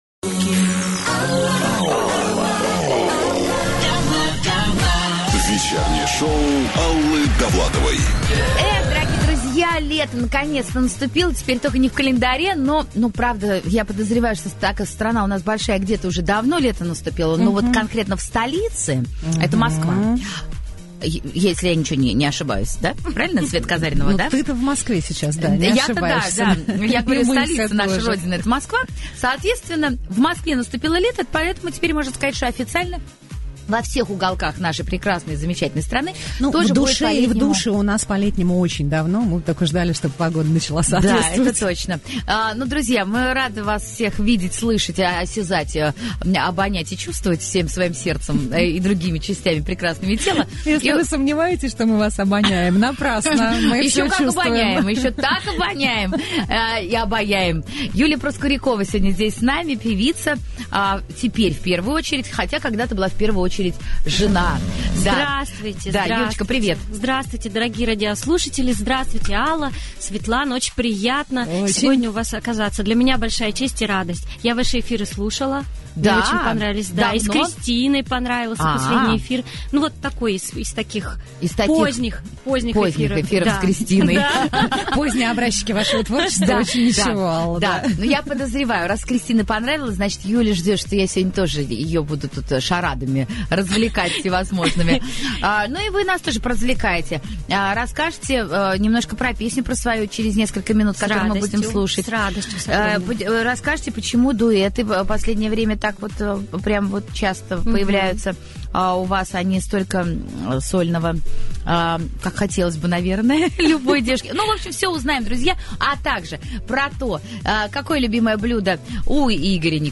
7 июня 2017 года в 20:00 в вечернюю студию "Русского радио" в гости к ведущей Алле Довлатовой придет очаровательная Юлия Проскурякова. Беседа двух молодых мам несомненно вызовет интерес у радиослушателей.
Также обсуждаются отношения в семье, поддержка мужа и значимость творчества для Юлии. Программа сопровождается музыкальными фрагментами и живой беседой о семье, музыке и детях.